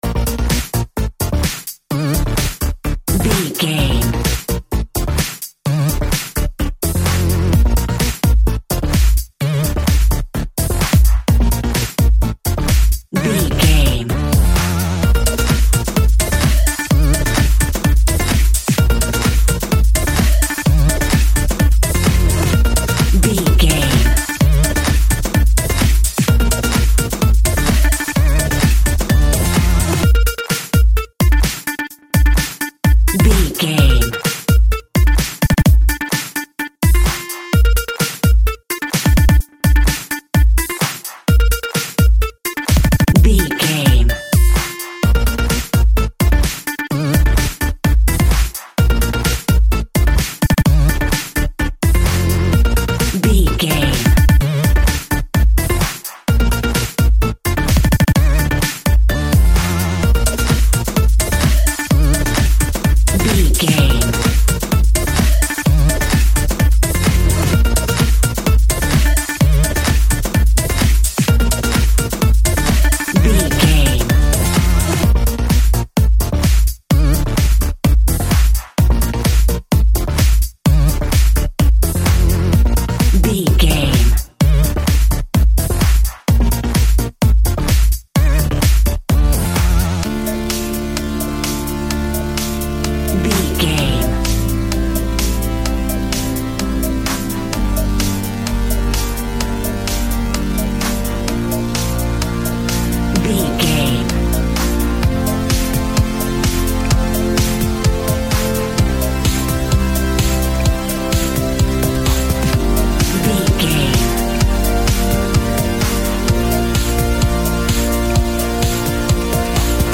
Funky Disco House Sounds from the Future.
Aeolian/Minor
B♭
aggressive
energetic
driving
drum machine
synthesiser
funky house
disco
groovy
upbeat
funky guitar
clavinet
synth bass
horns